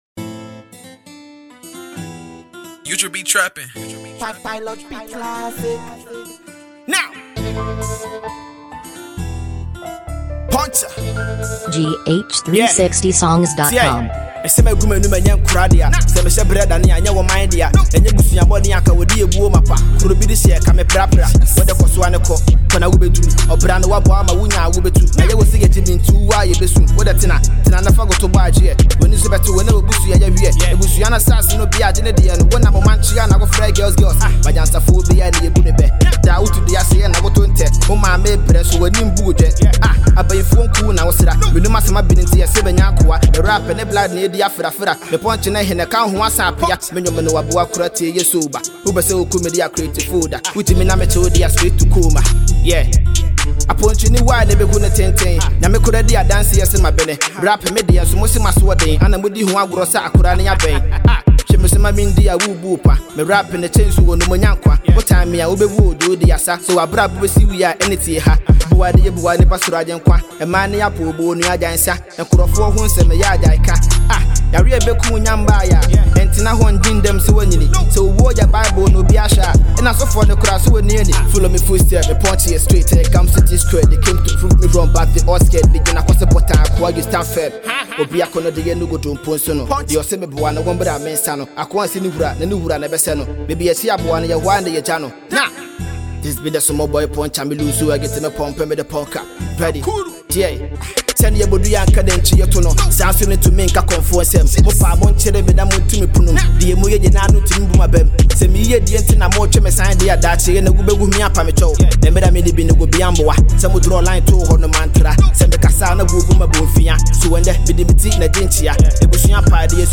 Ghanaian Talented Rapper